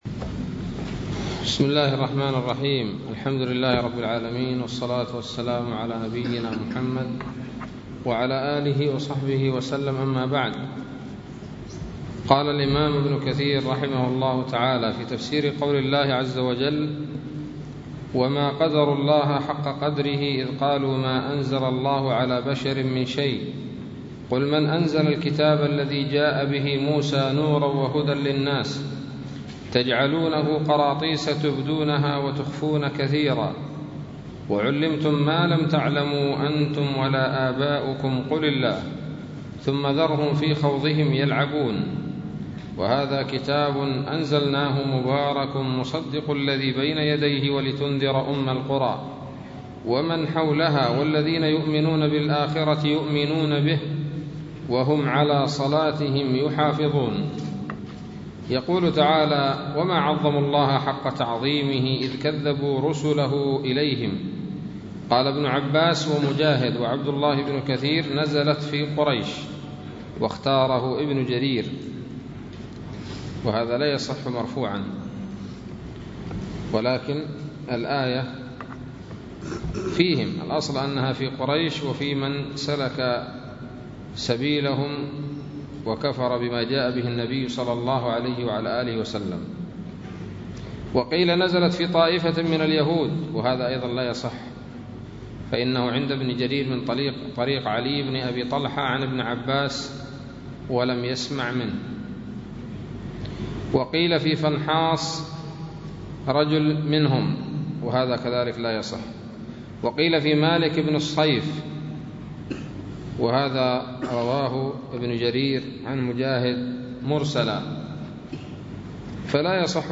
006 سورة الأنعام الدروس العلمية تفسير ابن كثير دروس التفسير